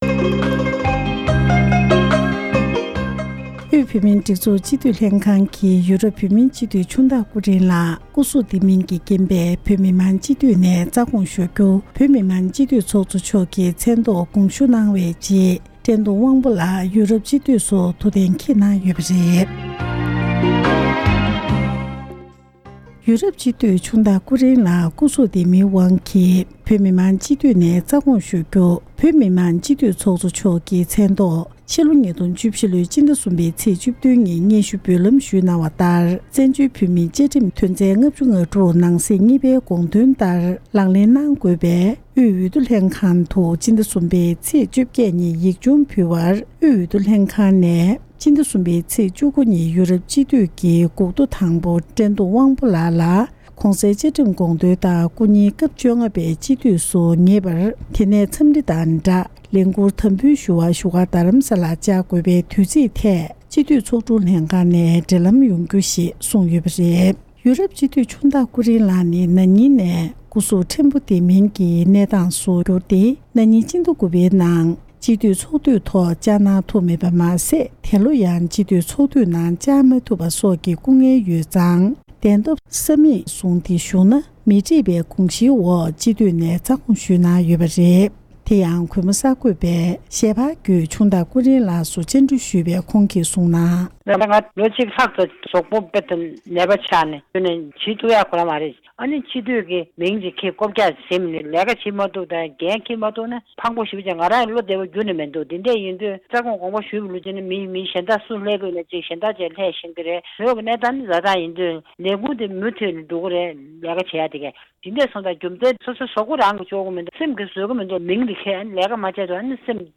ཡོ་རོབ་སྤྱི་འཐུས་ཆུང་བདག་ལགས་དགོངས་ཞུ། རྒྱས་པའི་གནས་འདྲིའི་ལེ་ཚན། སྒྲ་ལྡན་གསར་འགྱུར།